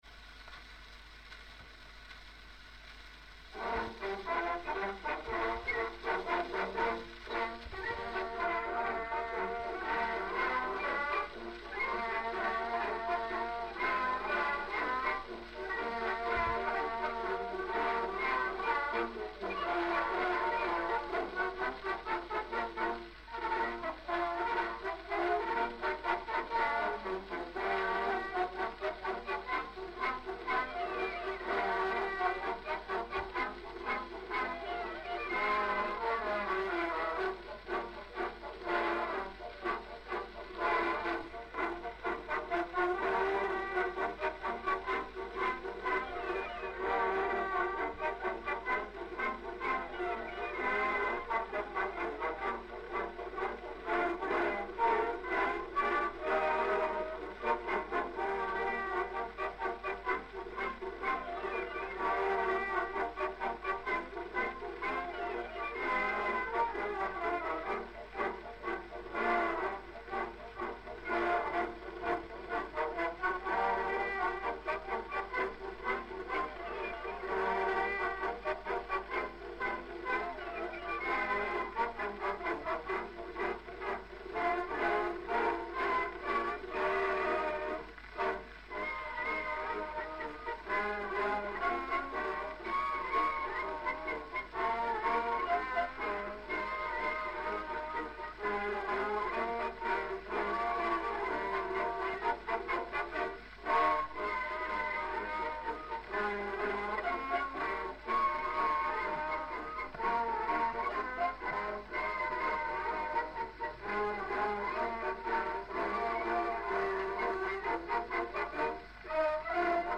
"Berliner Luft" or "Das ist die Berliner Luft" is a marching rhythm operetta song written by Paul Lincke in 1904 to a text by Heinrich Bolten-Baeckers.